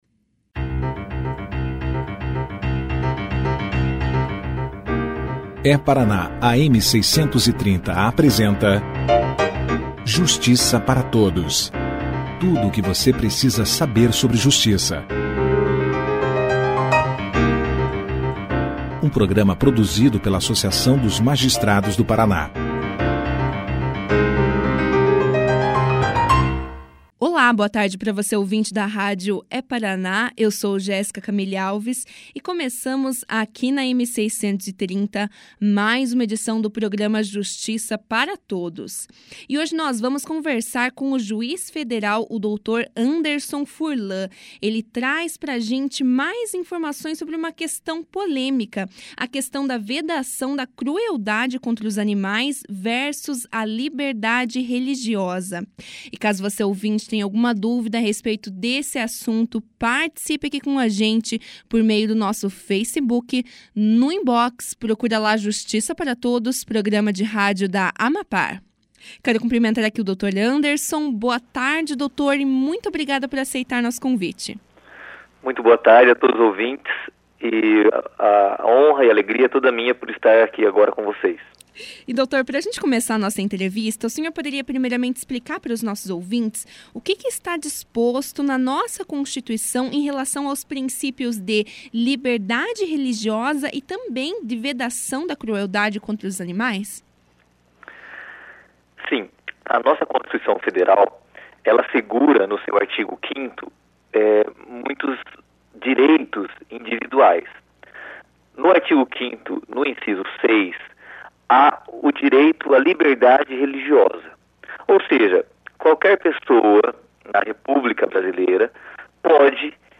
Nesta quarta-feira (13), o Justiça Para Todos conversou com o juiz federal Anderson Furlan, que falou sobre a polêmica envolvendo a vedação da crueldade contra os animais versus a liberdade religiosa.